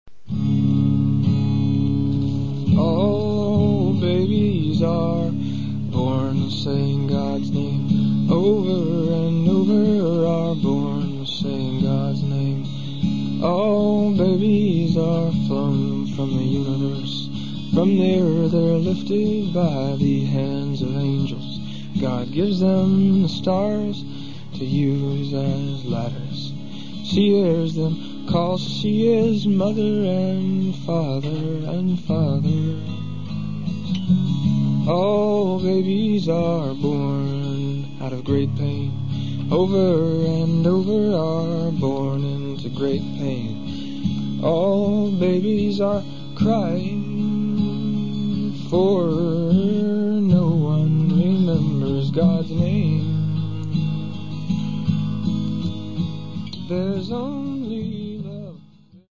Sessions radios & lives inédits
KSUF, San Francisco - 1998